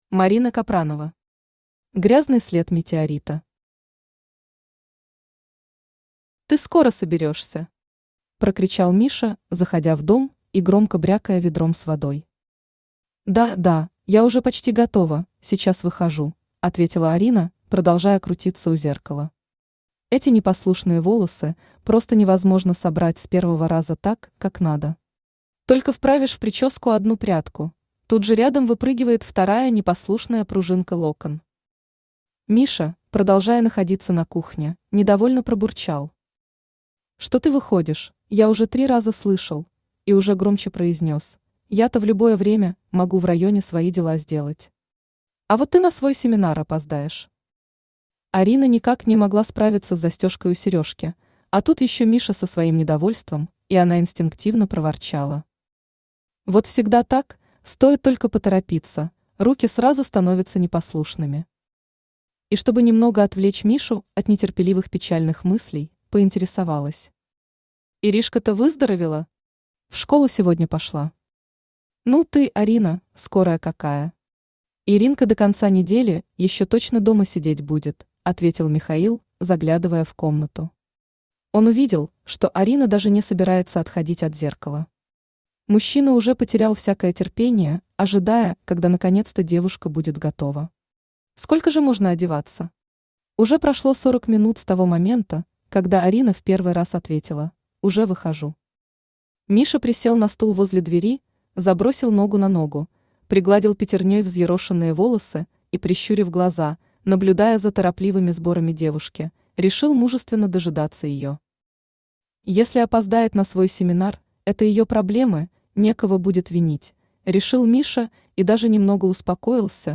Аудиокнига Грязный след метеорита | Библиотека аудиокниг
Aудиокнига Грязный след метеорита Автор Марина Капранова Читает аудиокнигу Авточтец ЛитРес.